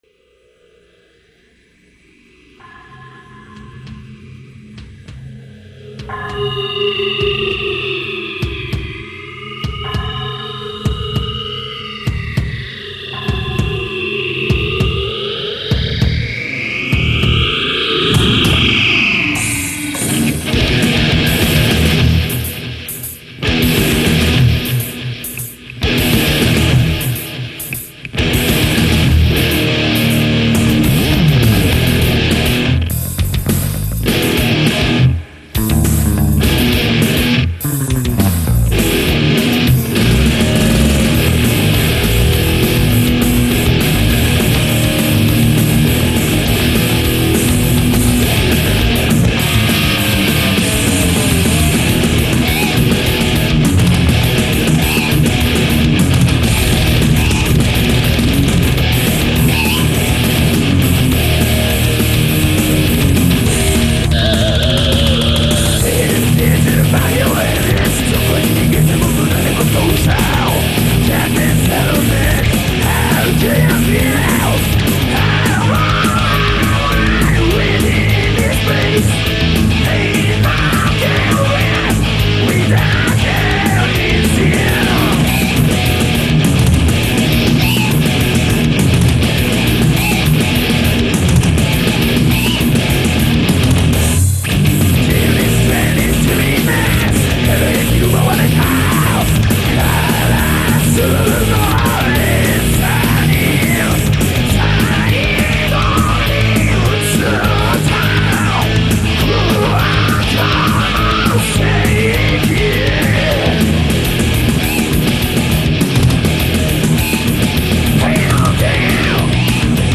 HARD ROCK系
＊ボリューム注意
チープなドラムマシーンからちょっと良いドラムマシーンをバンドで購入して作った宅録。
「マシーン」を使うのだから、どうせなら人間技では出来ない事しようぜ！という事でバスドラが早い（笑）